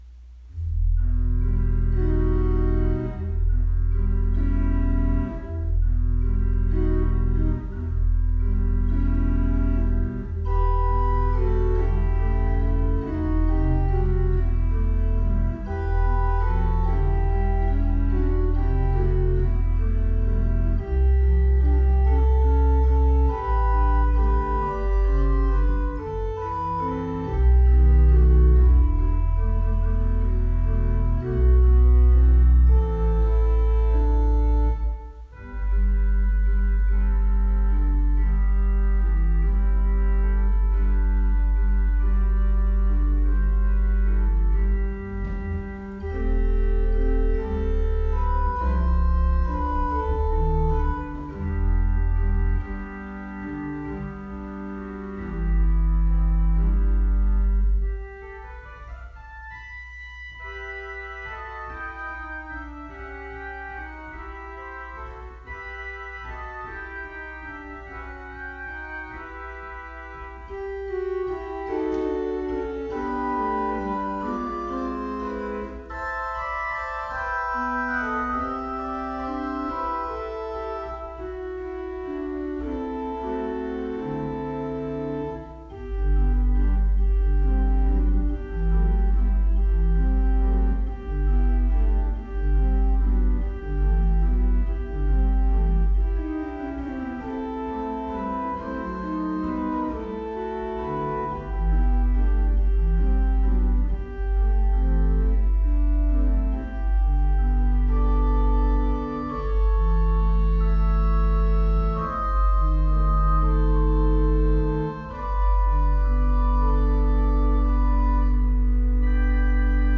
WORSHIP CELEBRATING THE EPIPHANY
PRELUDE: